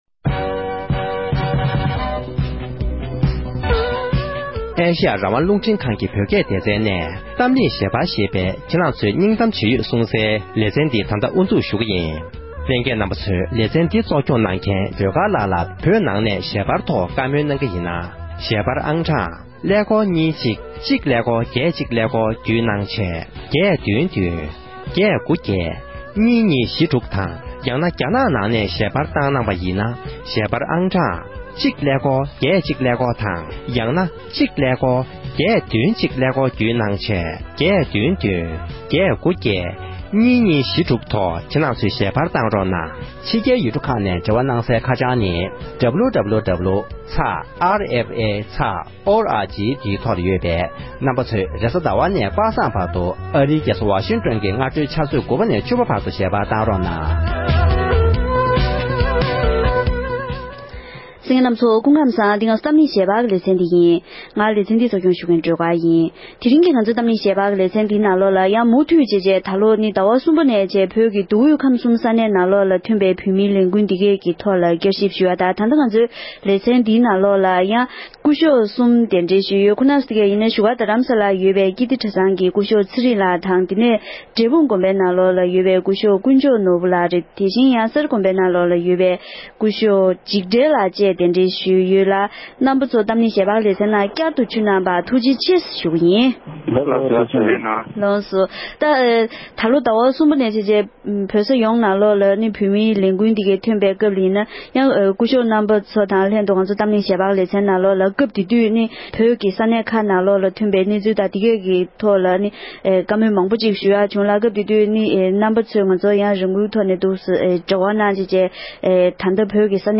གཏམ་གླེང་ཞལ་པར་གྱི་ལེ་ཚན